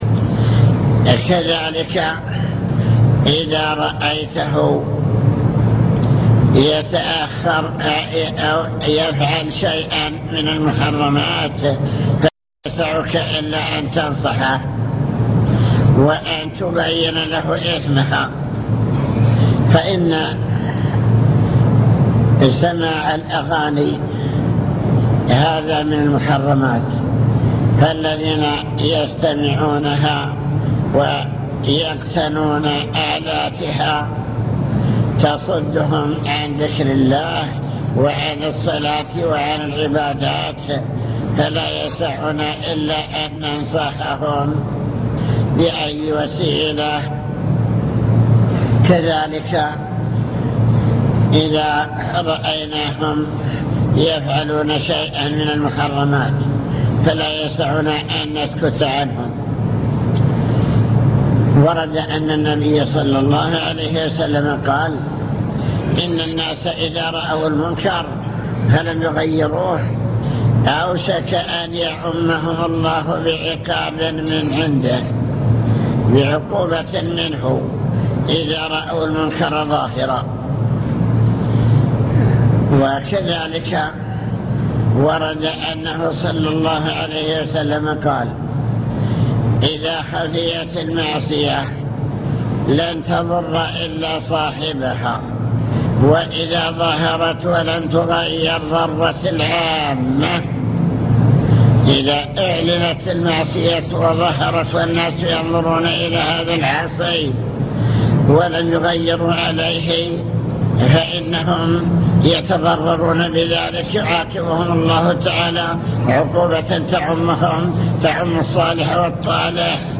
المكتبة الصوتية  تسجيلات - محاضرات ودروس  محاضرة في السعيرة